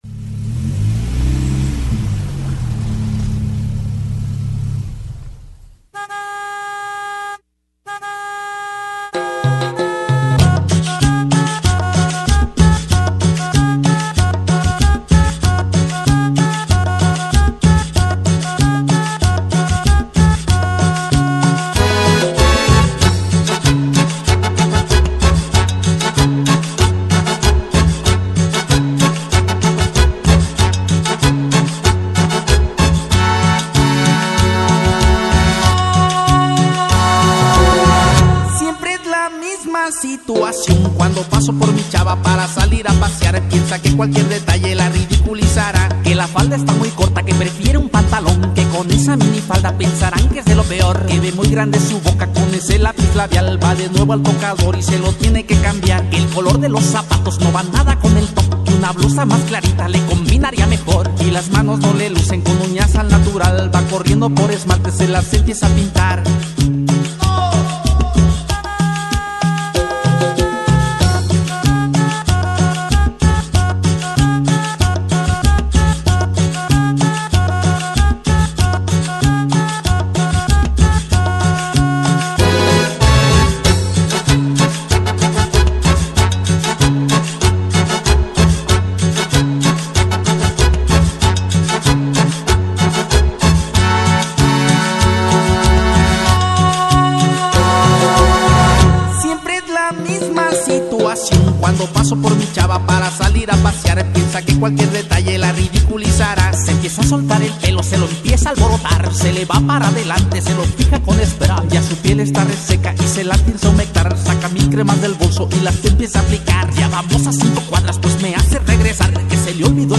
musica tropical/sonidera